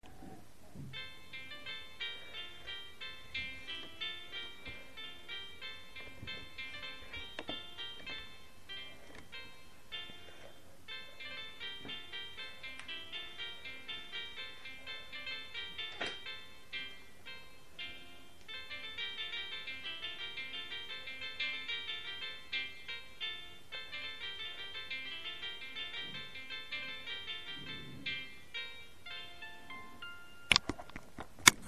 סקיצה נחמדה של מנגינה מוכרת..
יש כמה הפרעות ברקע...
זה חלש